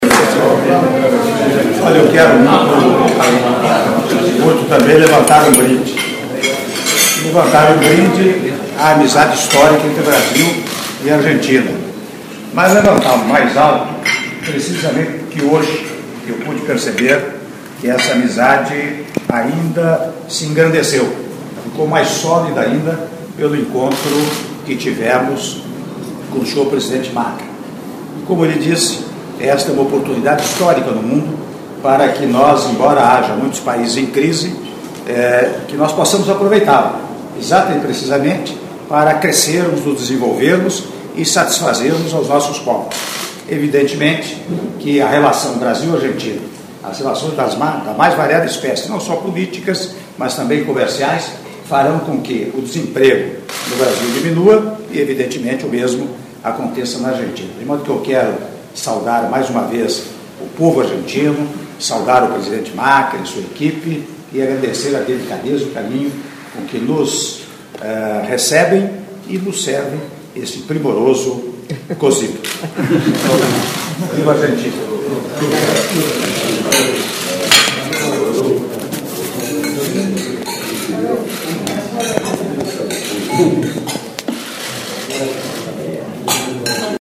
Áudio do brinde do presidente da República, Michel Temer, durante almoço oferecido pelo Presidente da República da Argentina, Mauricio Macri - Buenos Aires/Argentina (01min27s)